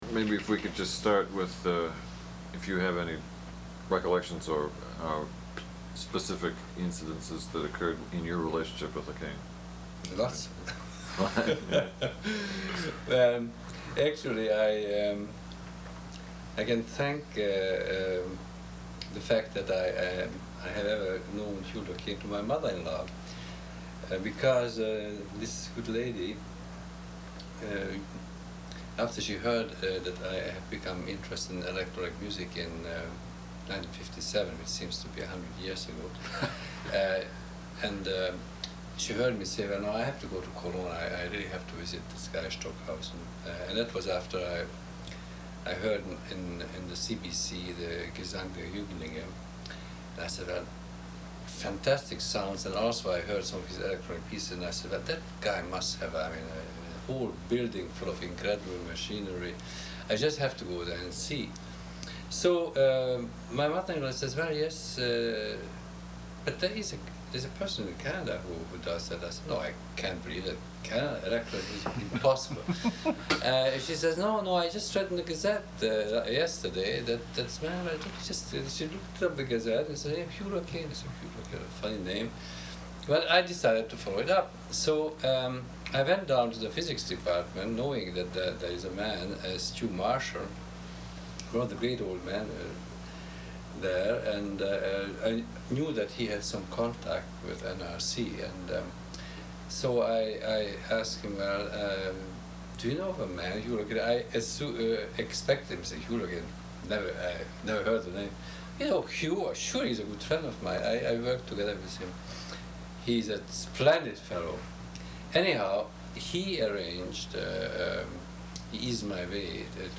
Interview with István Anhalt, Hungarian-Canadian Composer